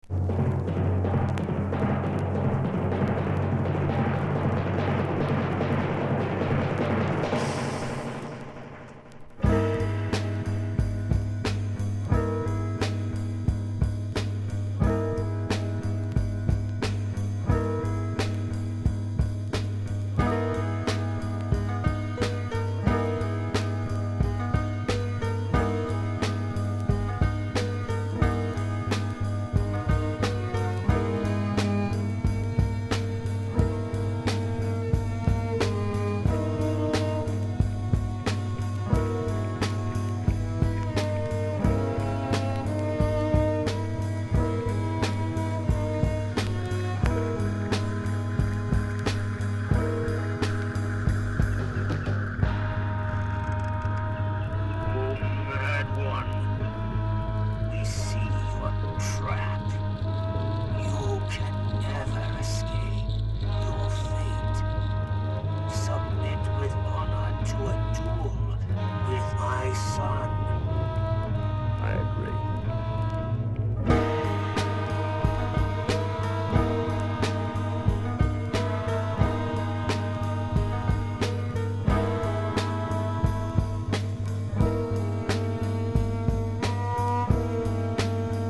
形式 : 7inch / 型番 : / 原産国 : USA